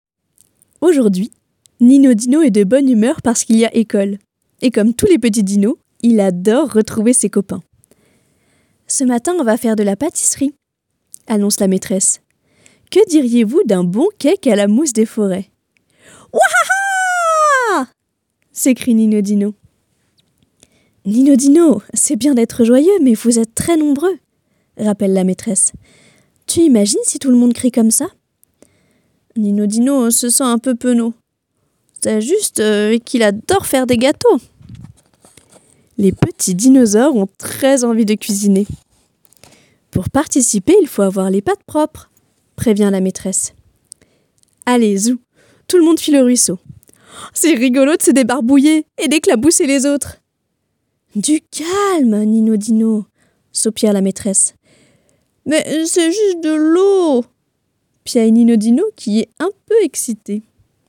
Voix off
maquette livre audio enfant